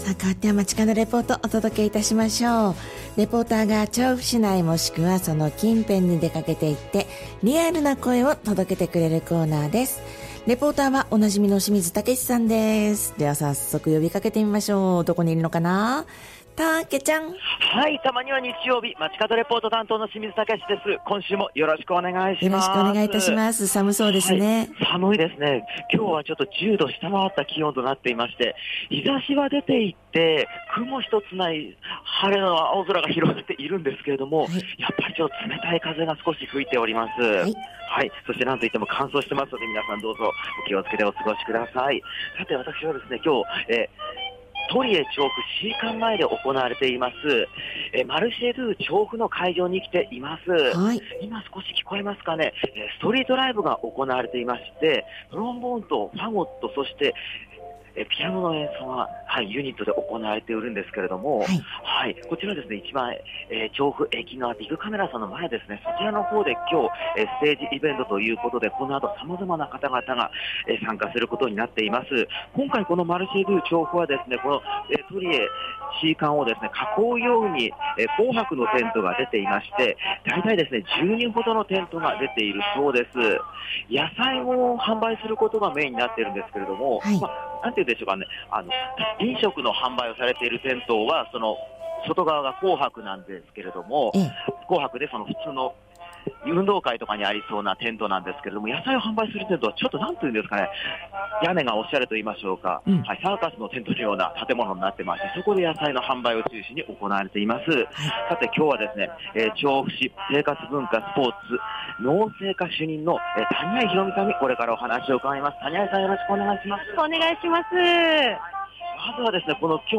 今週は10℃を下回った空の下から、お届けした本日の街角レポートは、「マルシェ・ドゥ・調布」が行われている調布駅前広場からのレポートです！！